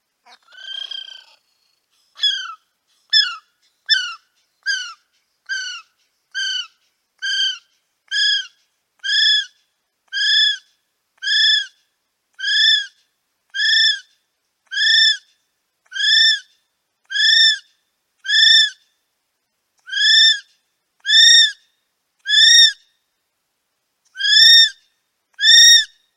Звуки птицы киви
На этой странице собраны звуки птицы киви — уникальной нелетающей птицы из Новой Зеландии. Вы можете слушать онлайн или скачать её голоса в формате mp3: от нежных щебетаний до характерных криков.